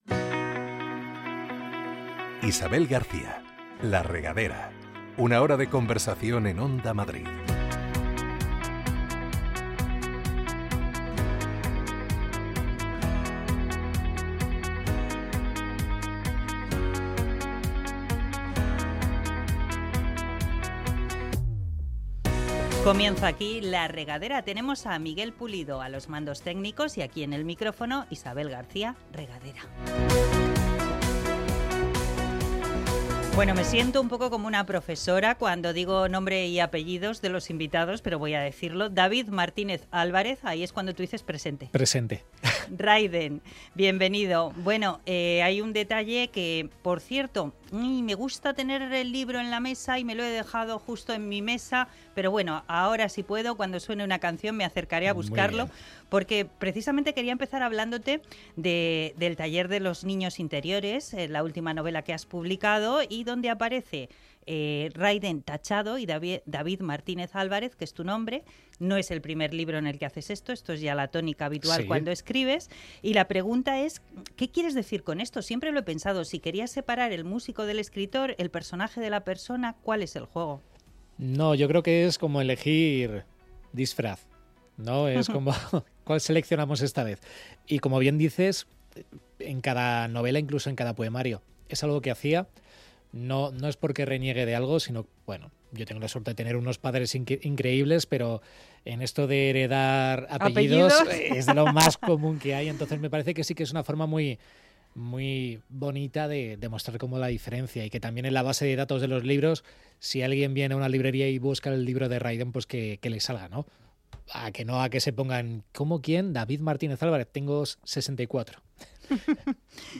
Este fin de semana, en la Regadera de Onda Madrid, la madrugada del sábado al domingo, a las doce de la noche charlamos con David Martínez Álvarez…Rayden…. David Martínez Álvarez, de nombre artístico Rayden, presenta su nueva novela El taller de los niños interiores, una historia coral de personajes heridos que buscan abrazar a los niños que fueron para seguir avanzando.